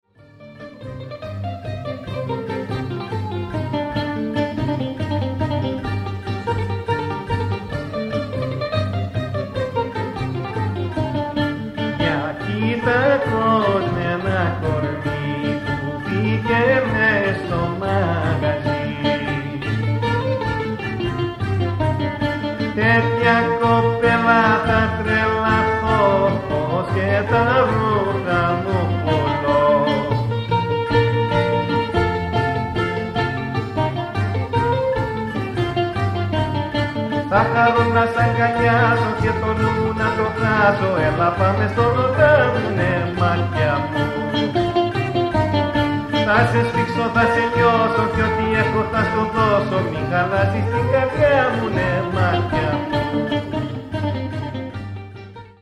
Karsilamas (Aidiniko or Andikrystos)